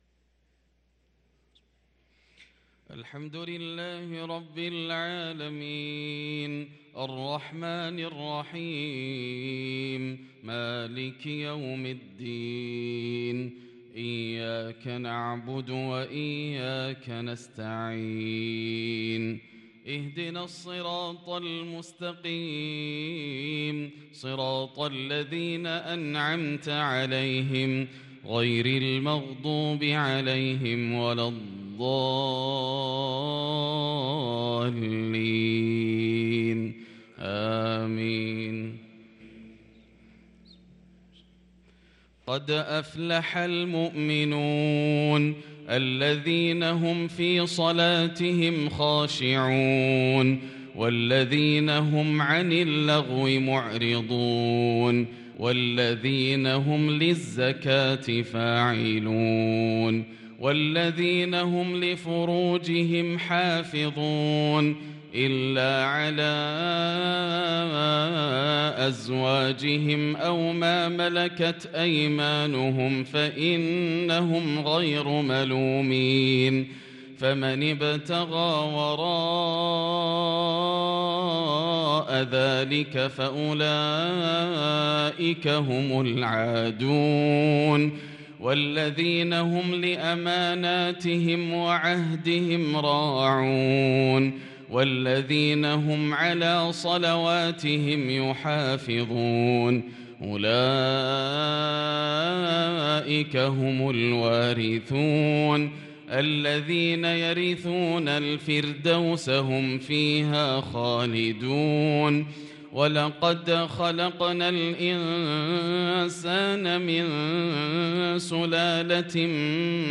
صلاة الفجر للقارئ ياسر الدوسري 8 شوال 1443 هـ
تِلَاوَات الْحَرَمَيْن .